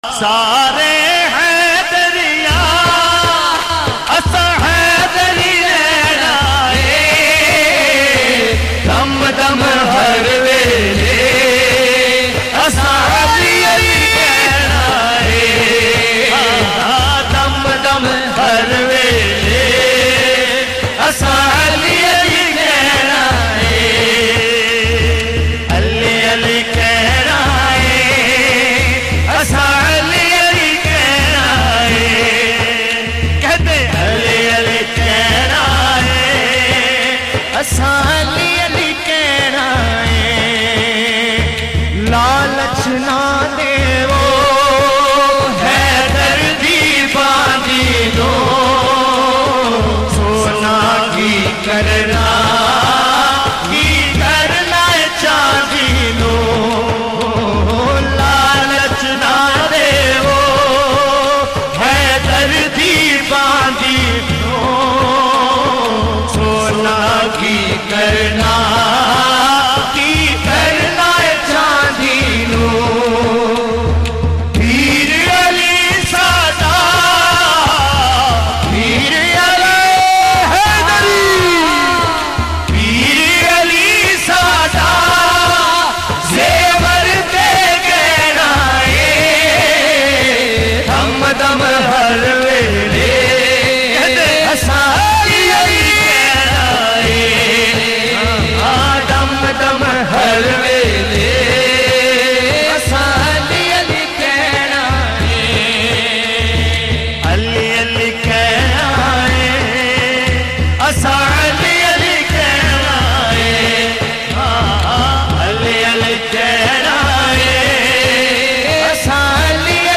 in a Heart-Touching Voice
naat khawan